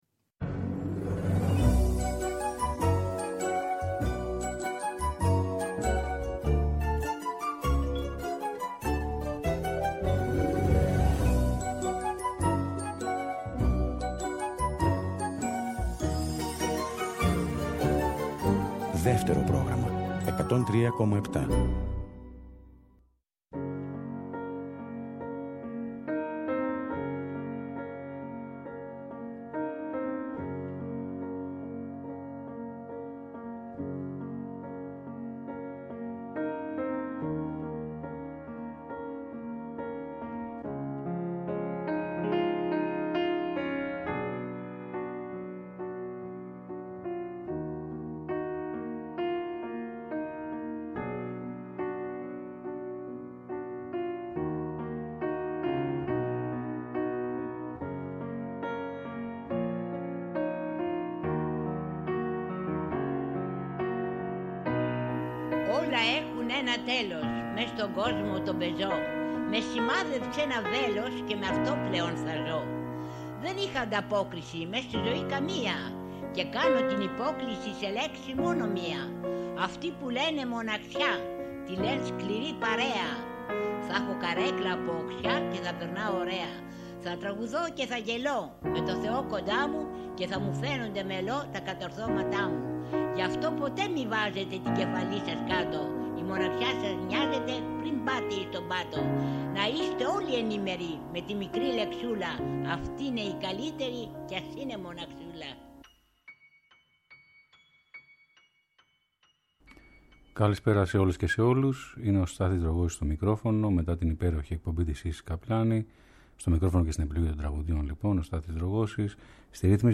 Ποιήματα ιστορίες και φθινοπωρινές ελεγείες. Τραγούδια αγαπησιάρικα μελαγχολικά καινούρια και παλιά αυτό το Σάββατο 7 Οκτωβρίου στην Αντέλμα στις 5 το απόγευμα ακριβώς!